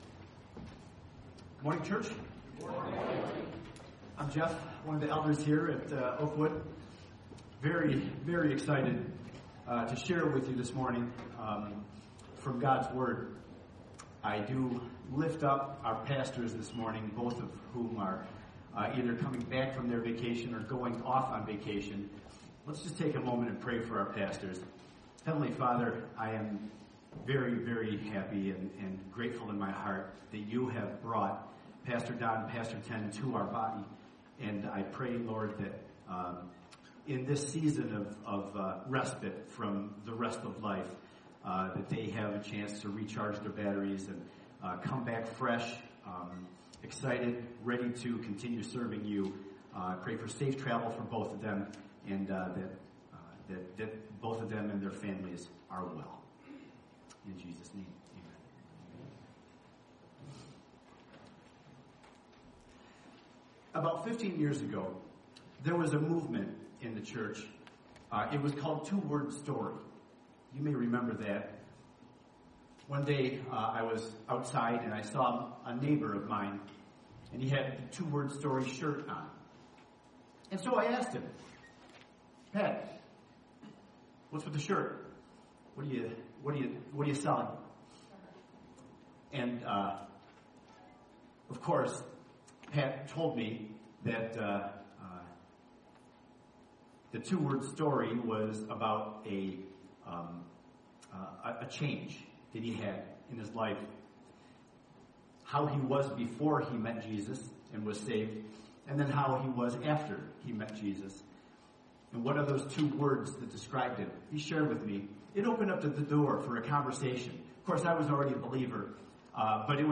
Oakwood Community Church Message Podcast | Oakwood Community Church